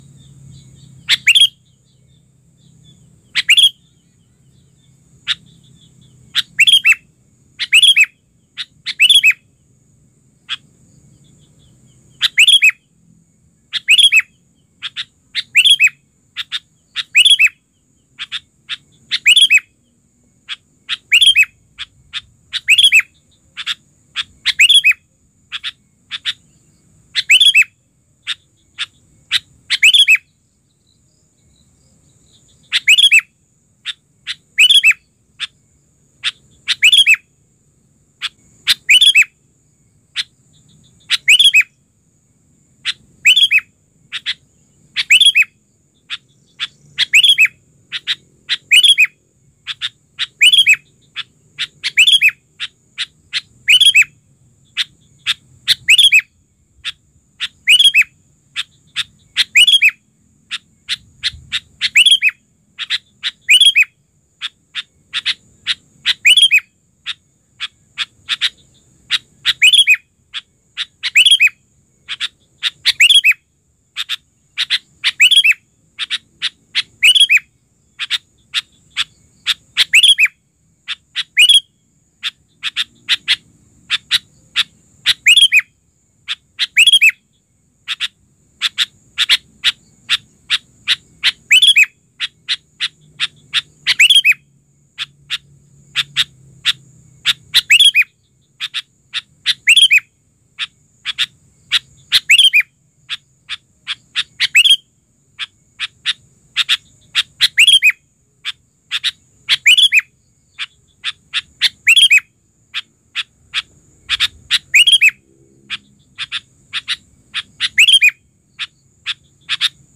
Kualitas suara dijamin jernih dan pas untuk masteran atau terapi burung Anda...
Suara Burung Kutilang Betina
Tag: suara burung betina suara burung kecil suara burung Kutilang
Suara burung Kutilang betina yang lembut dan natural ini efektif untuk bikin burung jantan nyaut dan makin gacor.
suara-burung-kutilang-betina-id-www_tiengdong_com.mp3